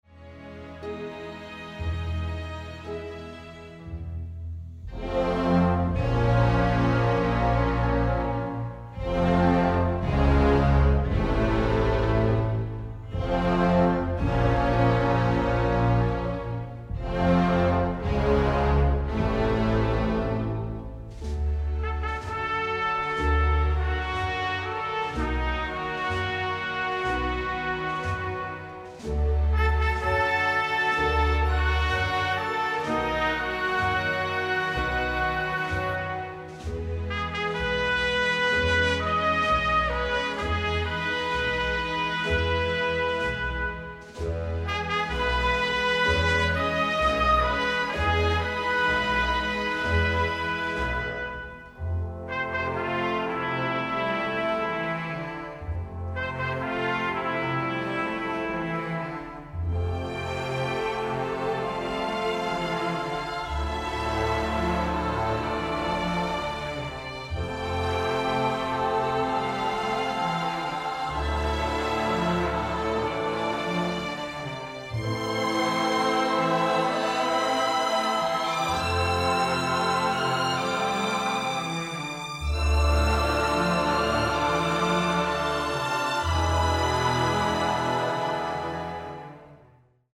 thrilling, often romantic score